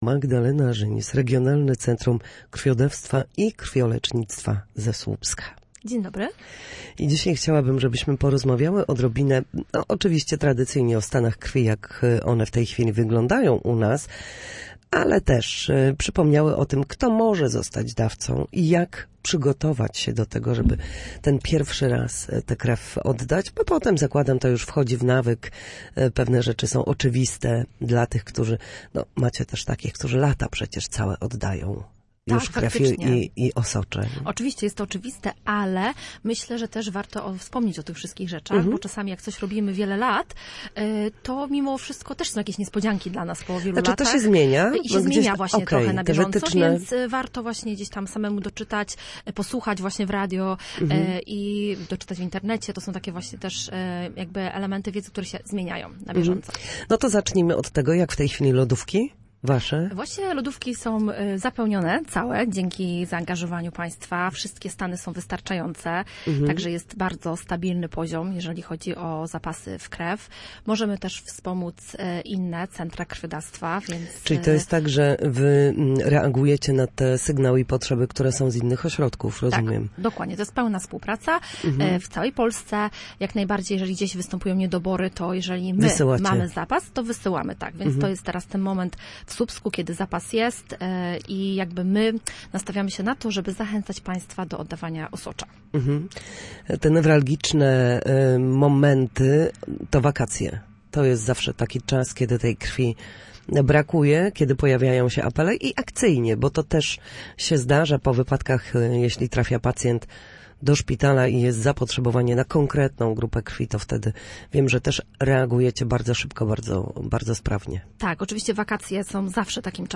Na naszej antenie mówiła o obecnych stan krwi oraz tym jak przygotować się do oddawania krwi lub osocza.